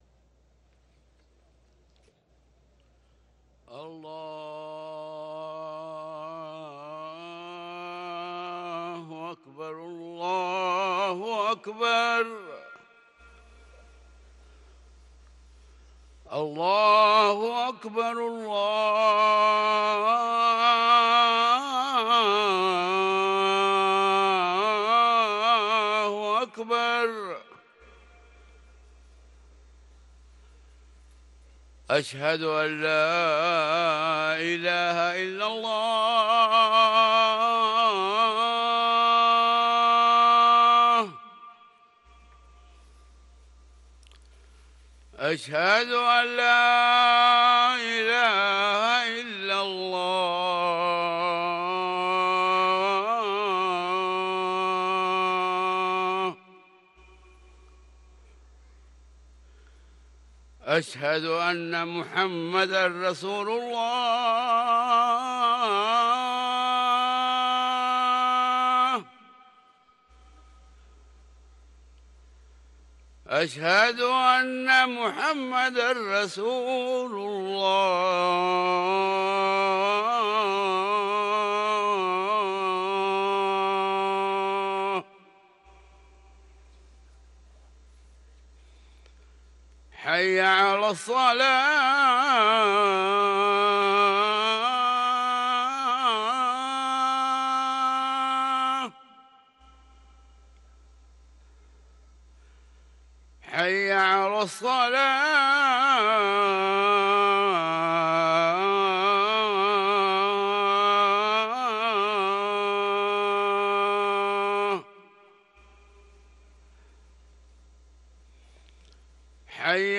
أذان الفجر للمؤذن